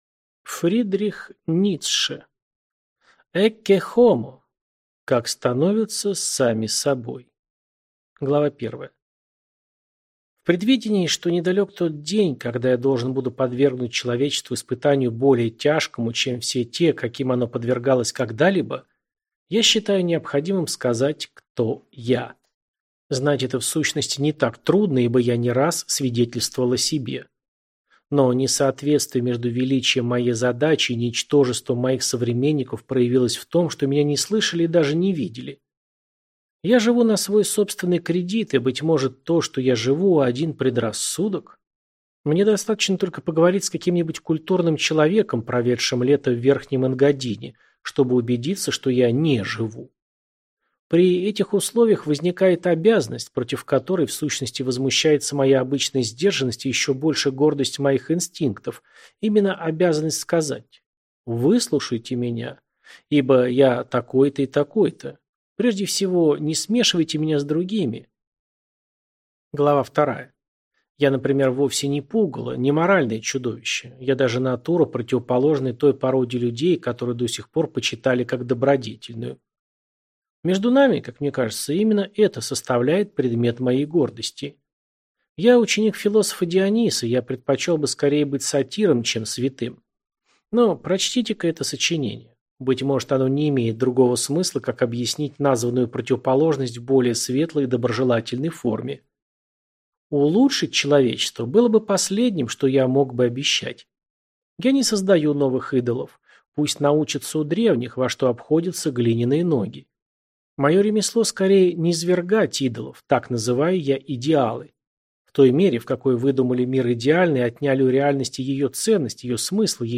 Аудиокнига Ecce Homo | Библиотека аудиокниг